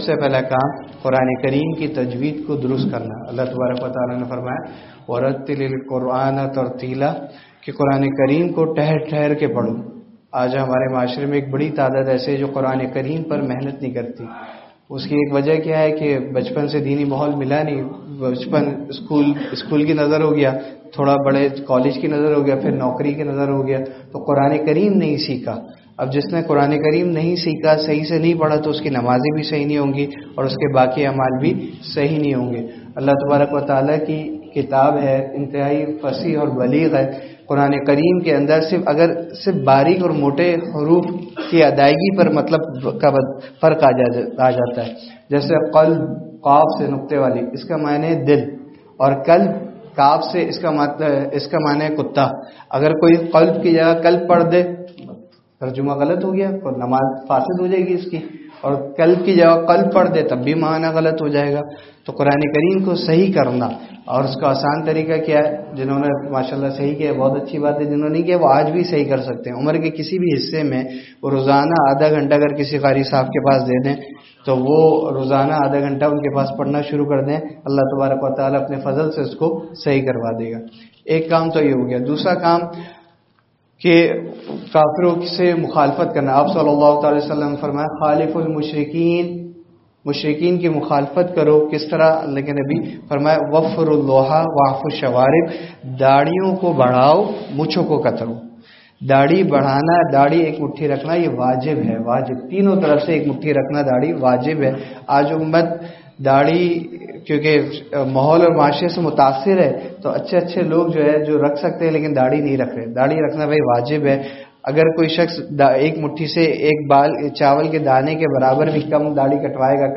Bayan After Maghrib at Jama Masjid Muhammadi, Noorani Basti Pull, Hyderabad